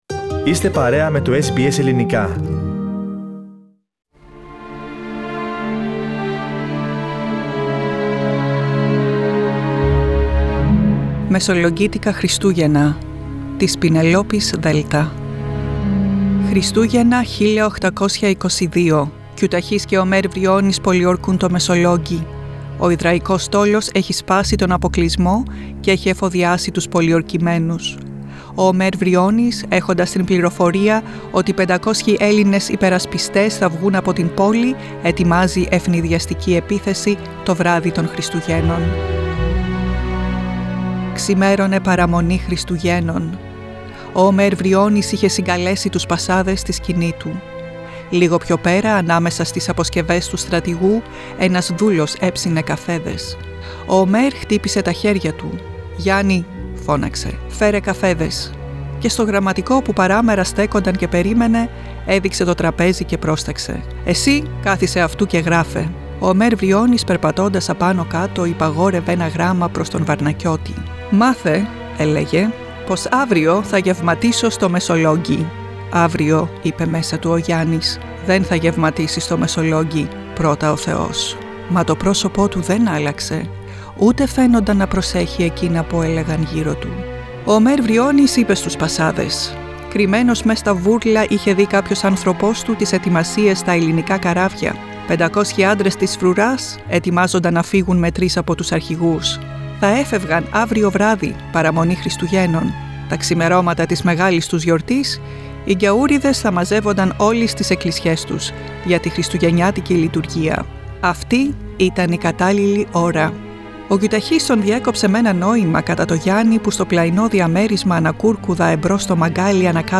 Δεκέμβρης 1822: παραμονή Χριστουγέννων και το πολιορκημένο Μεσολόγγι έμελε να δεχθεί επίθεση. Διήγημα της Πηνελόπης Δέλτα βασισμένο σε ιστορικά γεγονότα απ΄την Επανάσταση του '21.